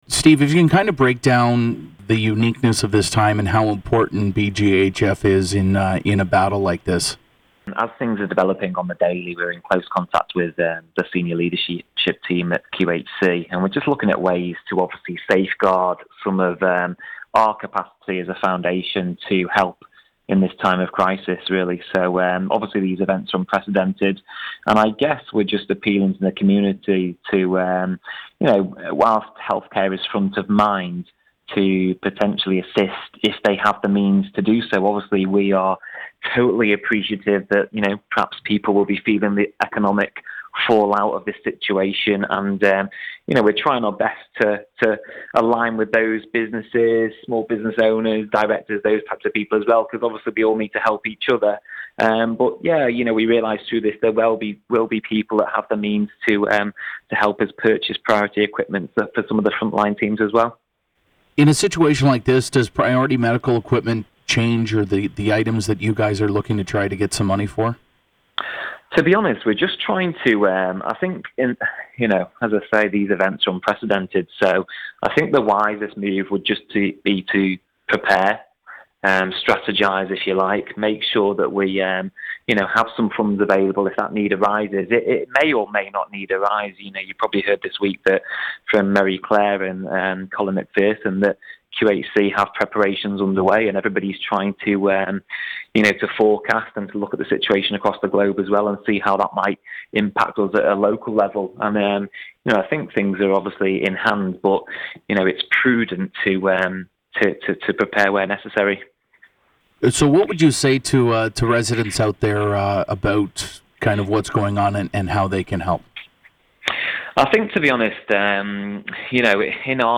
Here is his interview with Quinte News.